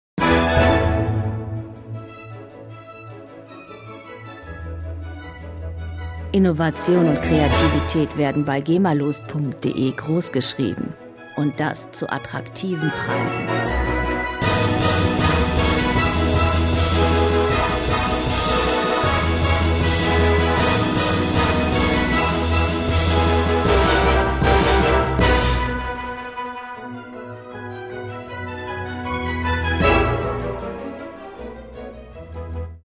Klassische Musik - Perlen der Klassik
Musikstil: Polka
Tempo: 156 bpm
Tonart: A-Dur
Charakter: lustig, beschwingt
Instrumentierung: Orchester